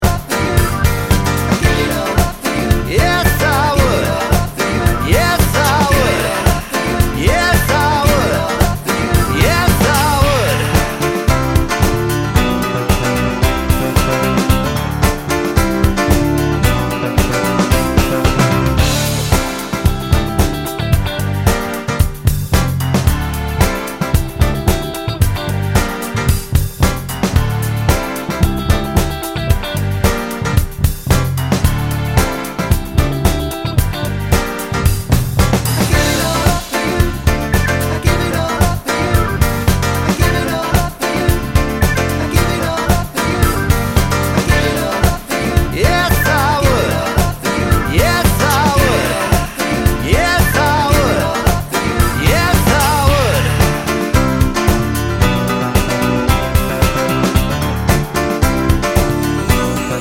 Minus Sax Solo Pop (1980s) 3:56 Buy £1.50